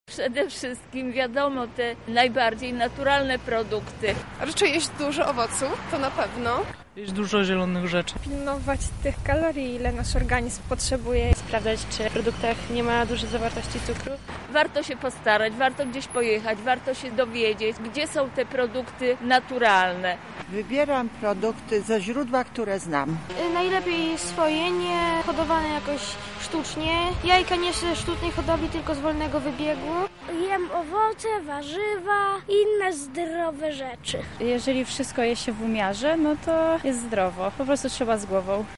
O tym rozmawiała z przechodniami nasza reporterka: